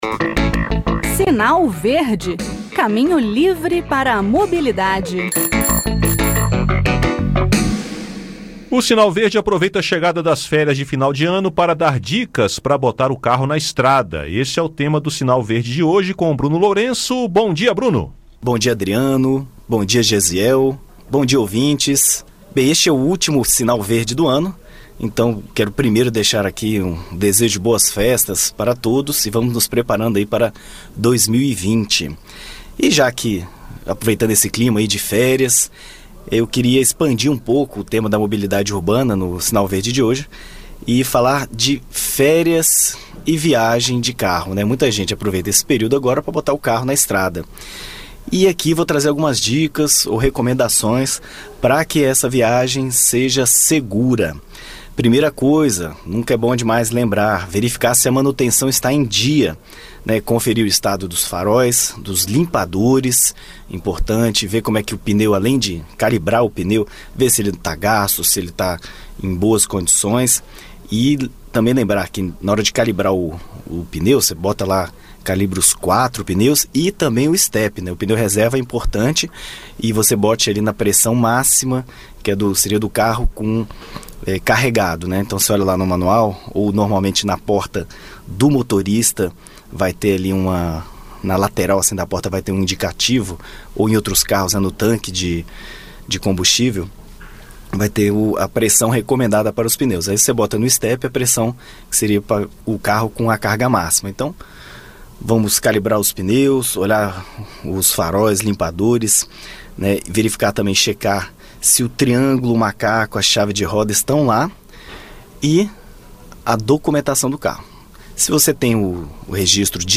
No "Sinal Verde" desta quarta-feira (18), o assunto são as recomendações para viagens de carro no final do ano. Ouça o áudio com o bate-papo.